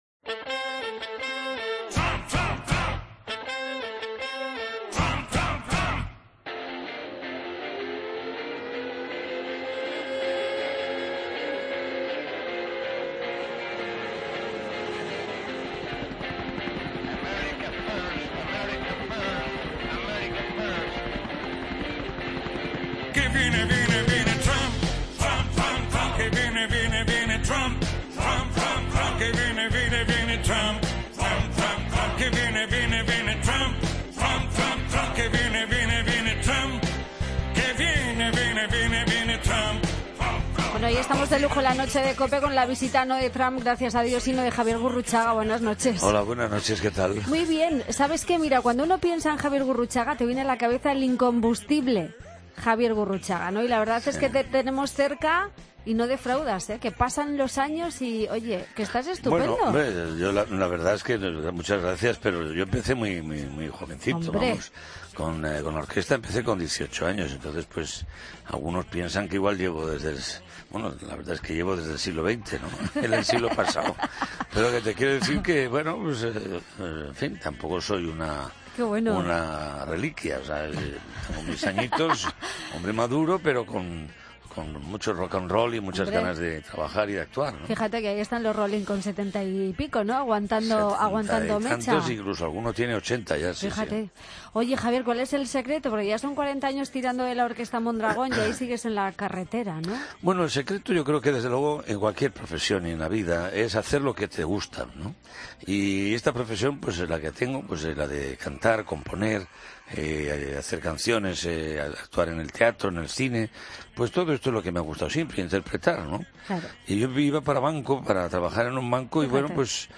ESCUCHA LA ENTREVISTA COMPLETA A JAVIER GURRUCHAGA